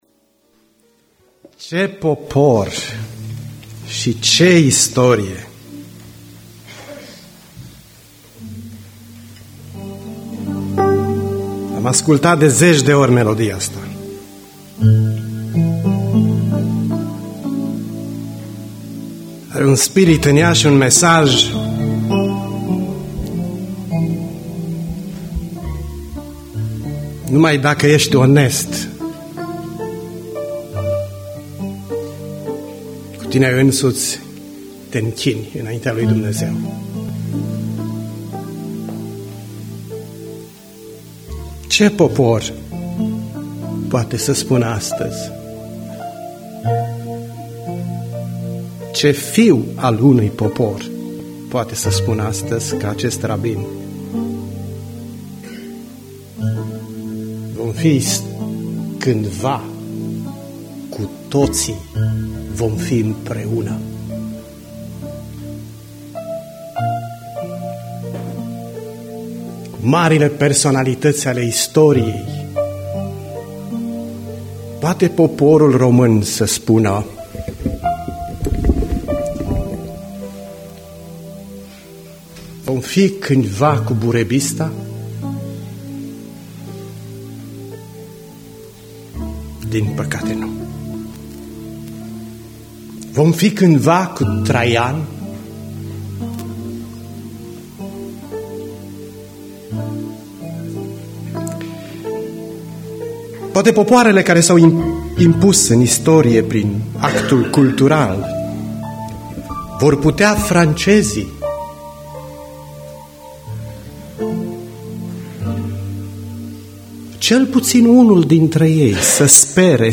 Predica Aplicatie- Ieremia 30-31